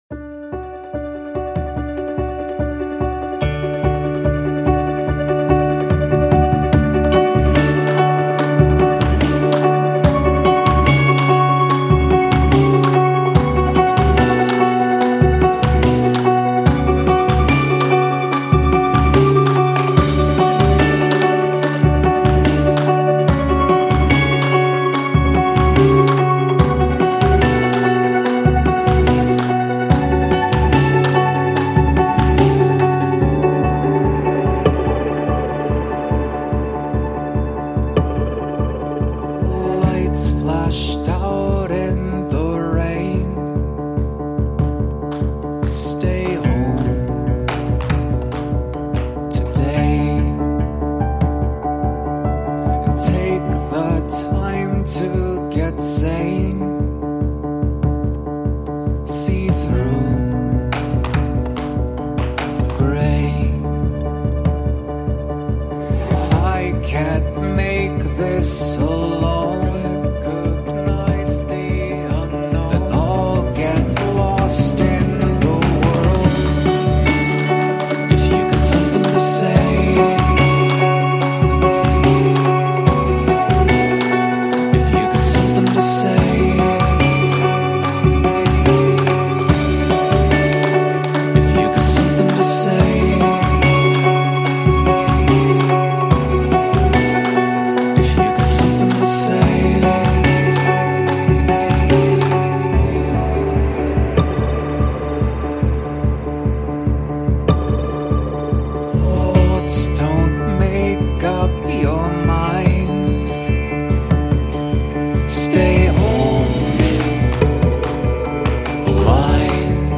electro-rock